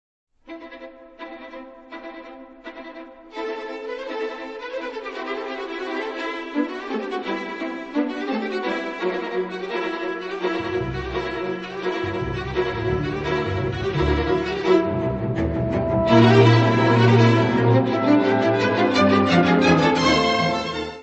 violoncelo
: stereo; 12 cm + folheto
Music Category/Genre:  Classical Music